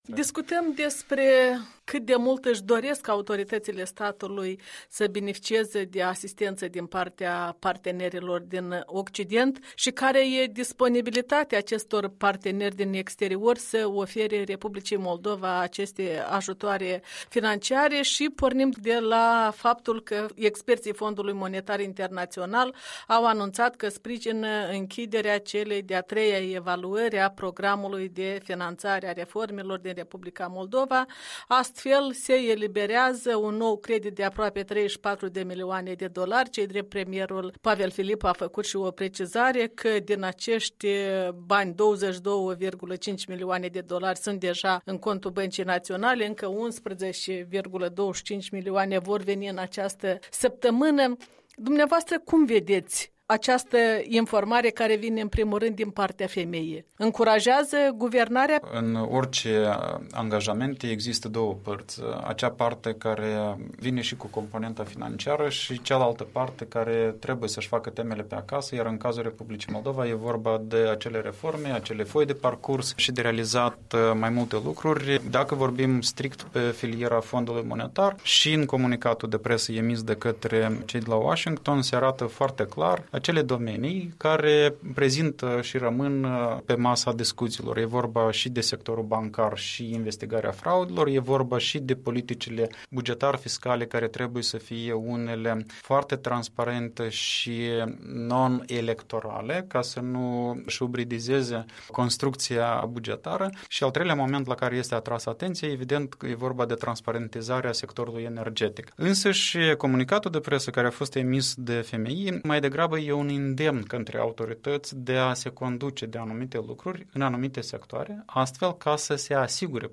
Interviu cu fostul ministru moldovean de finanțe.